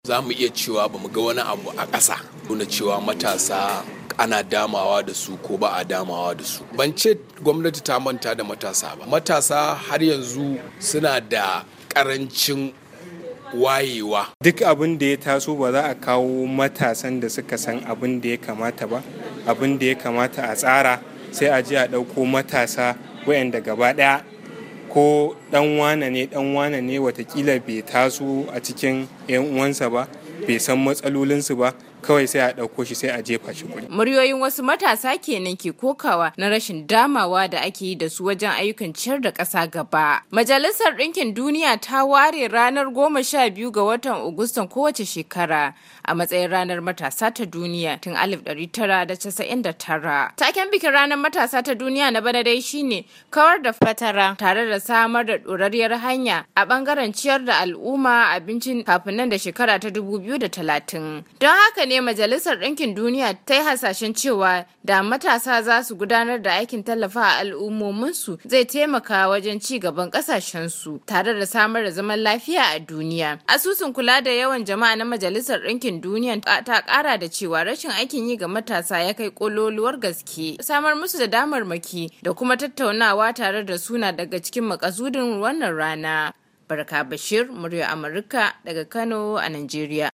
Gwamnatin jihar Kano ta ce , ta tura wasu matasa 100 zuwa kamfanin hada motoci ta ‘Peugeot’ da ke Kaduna don zama masu dogaro da kan su, a yunkurin taimakawa matasa. Kwamishinan yada labarai harkokin cikin gida matasa da al’adu na jihar Kano, Mohammad Garba ya bayyana haka a wata tattaunawa da...